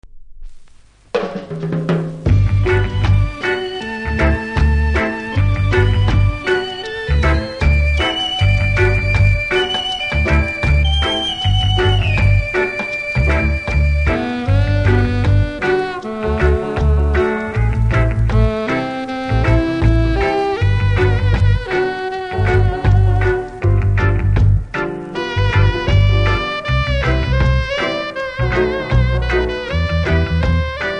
両面後半キズとプレス起因のノイズあります。